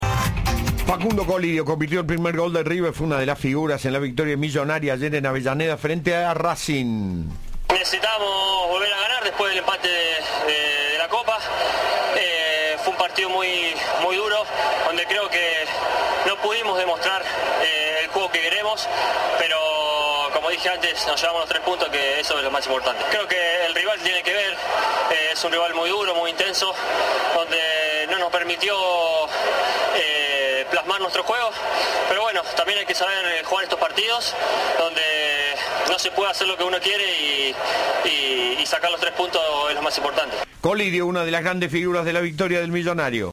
La ministra de Educación de la Ciudad de Buenos Aires dialogó con Cadena 3 Rosario y brindó un panorama sobre el comienzo del ciclo lectivo, habló de Populismo Educativo y fuertes críticas contra los gremios.